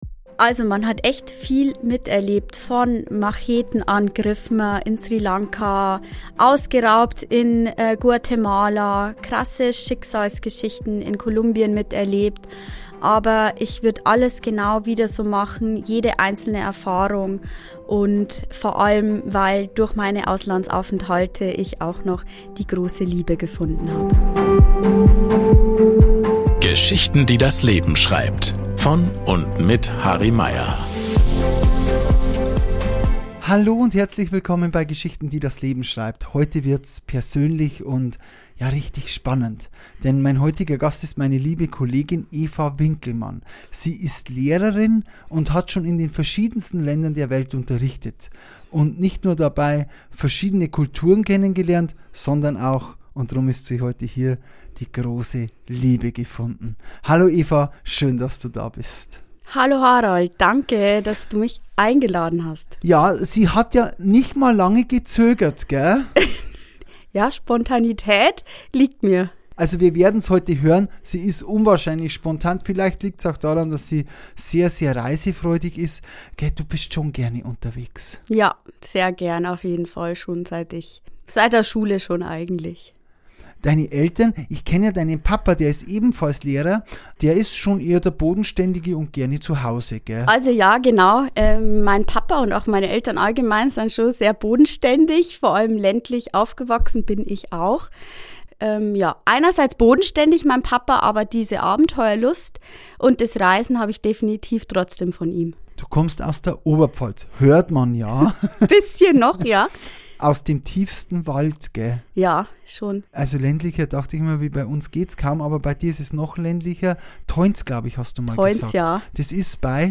Ein Gespräch über Fernweh, Heimweh, kulturelle Unterschiede, mutige Entscheidungen und die Frage, was Heimat wirklich bedeutet.